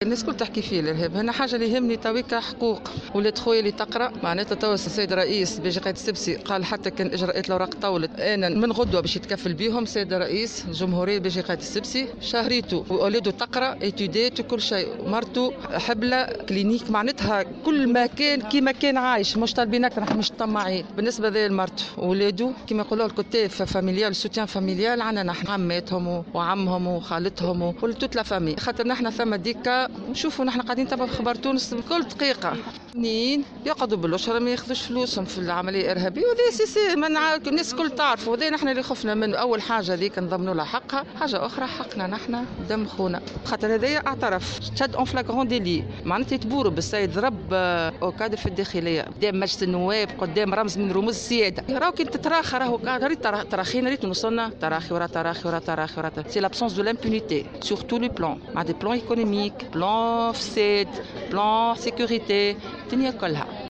Témoignage de la sœur du martyr t√©l√©charger partager sur